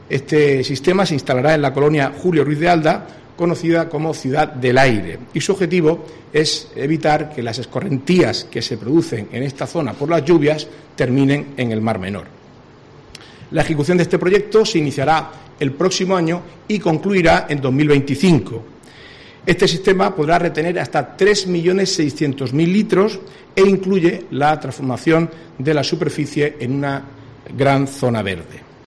Marcos Ortuño, portavoz del Gobierno Regional